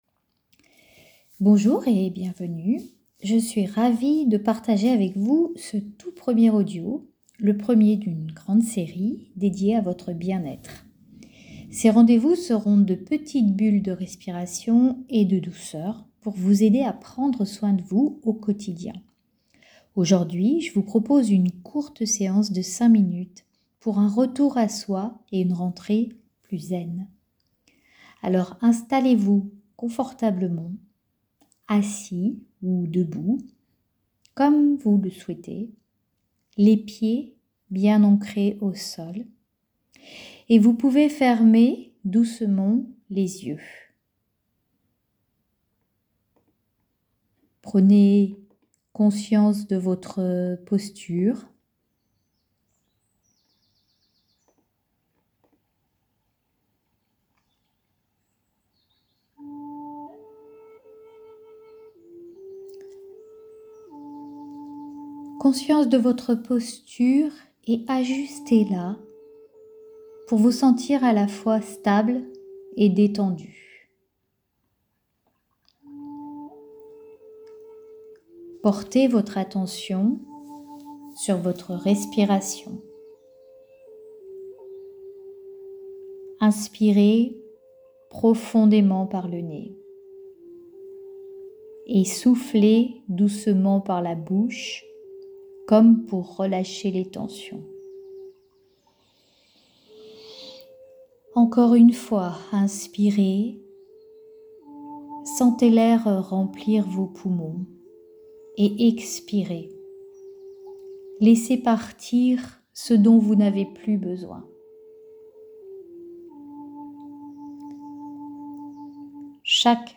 Vous y trouverez des articles inspirants et des audios guidés pour vous accompagner au quotidien.